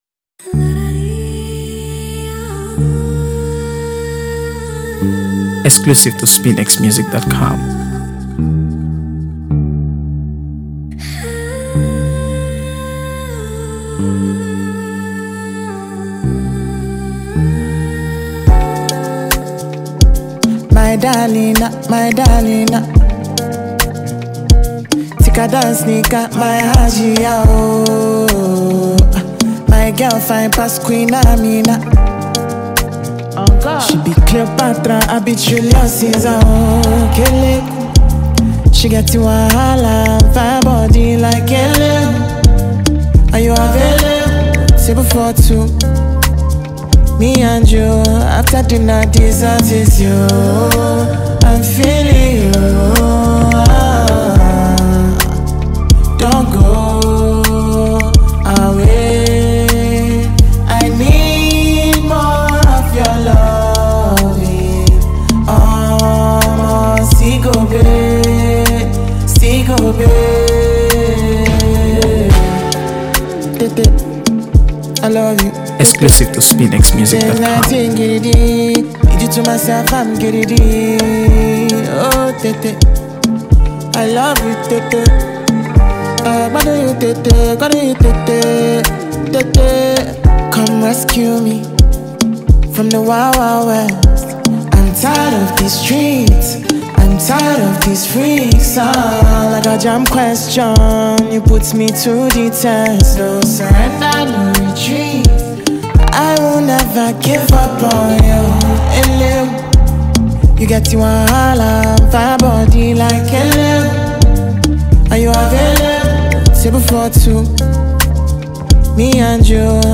AfroBeats | AfroBeats songs
Nigerian singer-songwriter
With its catchy hook and upbeat energy